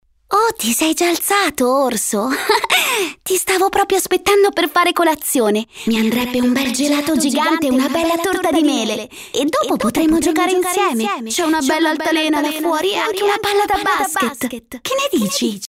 Commercial, Deep, Young, Natural, Distinctive
Her voice is basically young, deep and calm, but also energetic, gritty, characterful.